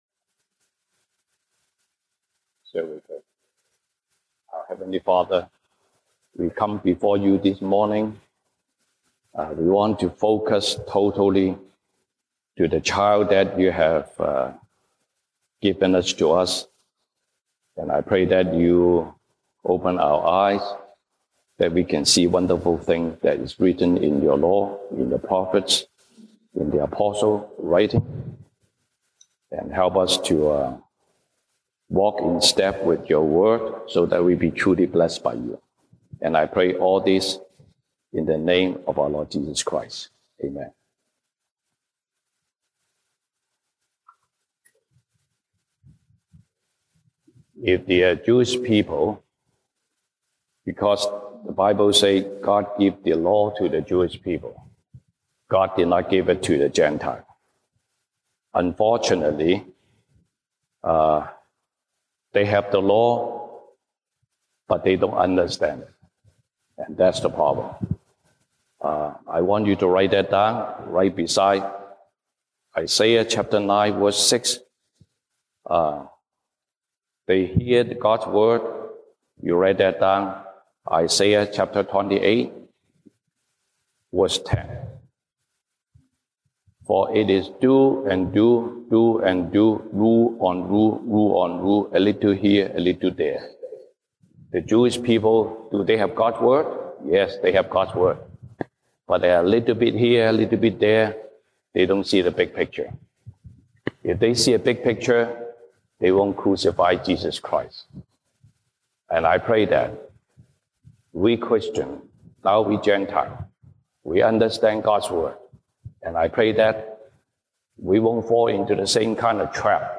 西堂證道 (英語) Sunday Service English: 以賽亞書 Isaiah 9:6-7